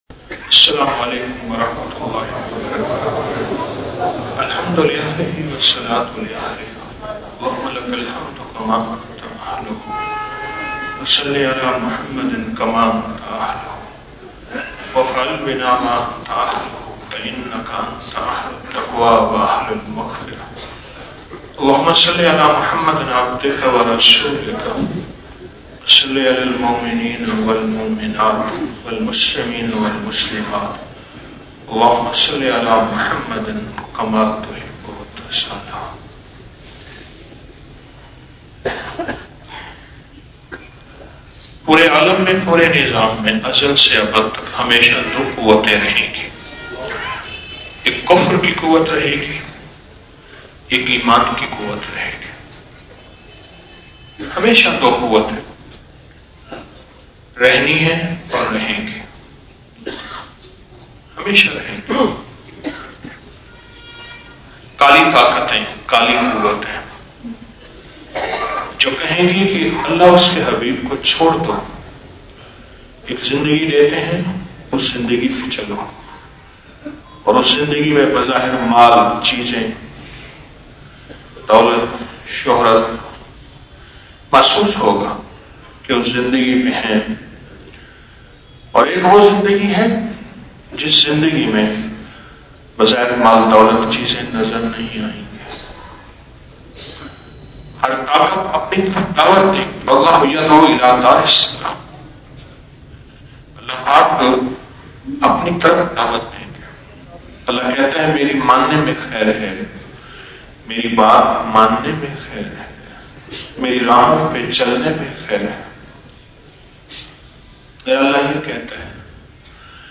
Audio Speech -